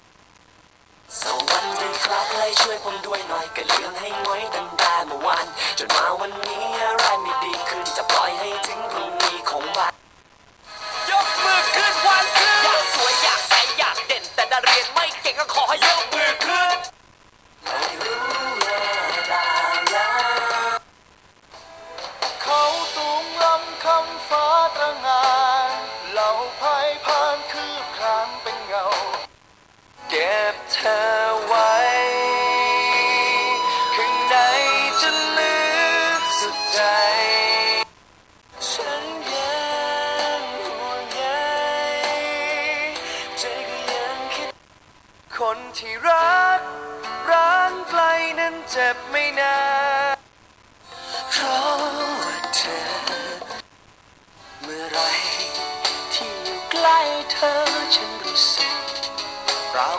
เสียงเรียกเข้า MP3 Real Sound รุ่นนี้จะรองรับไฟล์เสียงแบบ MP3, WAV, AMR, MIDI, i-Melody, ฯลฯ ซึ่งสามอย่างแรกเป็นเสียงแบบ Real Sound หรือเสียงเรียกเข้าสมจริง โดยจะใช้ลำโพงเสียงตัวเดียวกับสายสนทนา ในที่นี้ได้นำตัวอย่างเสียงเรียกเข้าบางส่วนมาทดลองฟังกัน (WindowMedia)